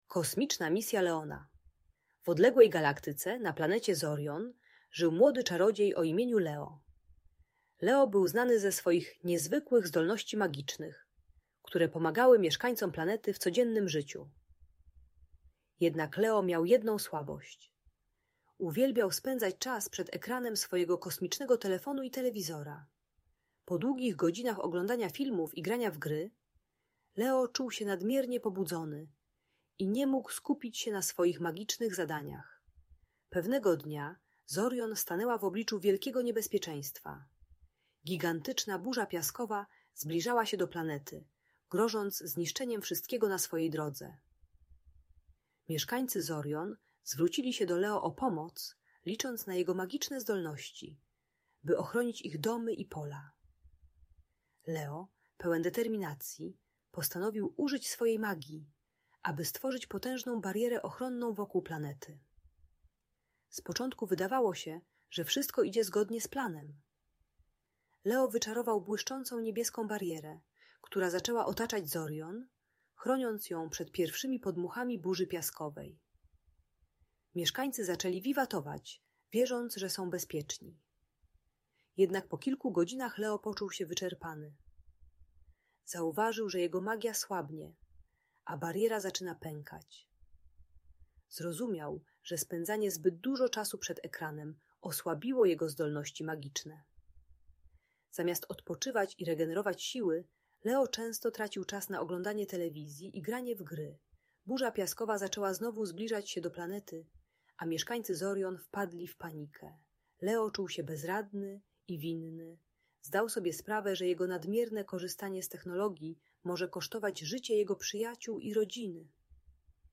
Kosmiczna Misja Leona - Historia o Równowadze i Magii - Audiobajka